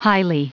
Prononciation du mot highly en anglais (fichier audio)
Prononciation du mot : highly